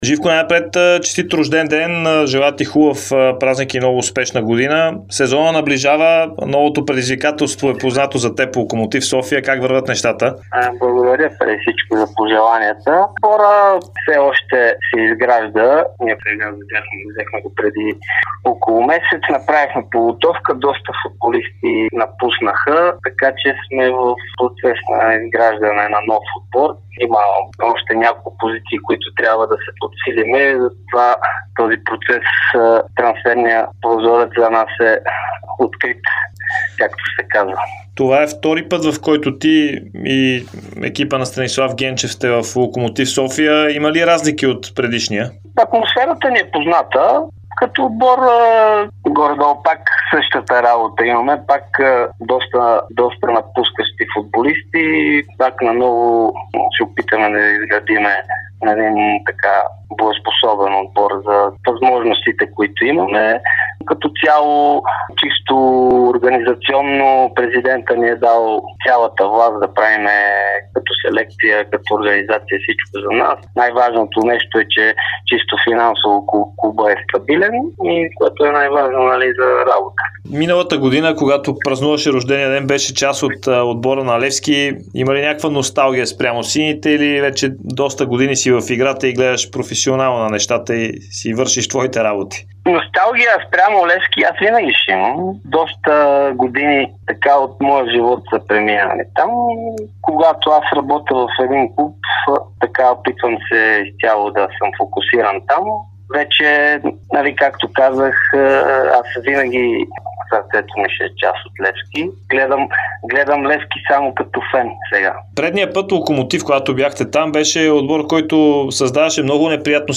Бившият капитан и помощник-треньор на Левски - Живко Миланов, настоящ асистент на Станислав Генчев в Локомотив София, говори за Дарик радио и Dsport. Той коментира раздялата на „сините“ с Джавад Ел Джемили.